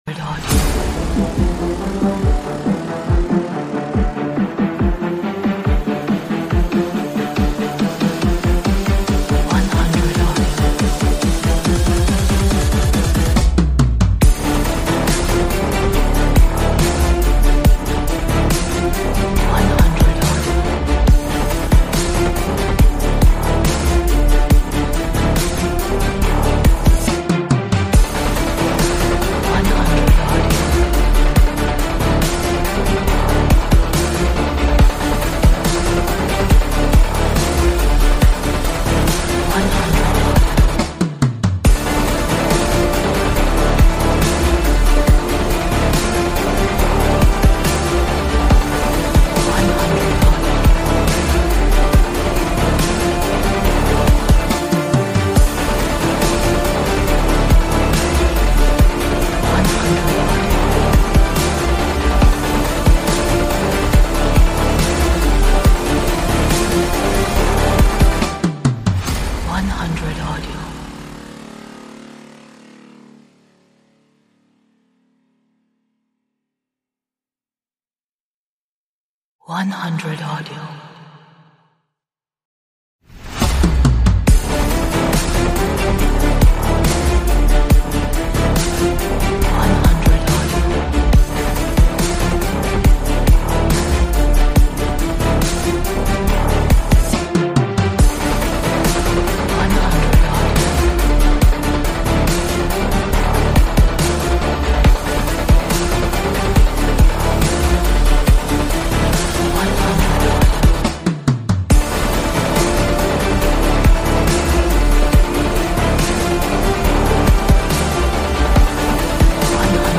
Atmospheric, dramatic, epic, energetic, romantic, dreamy,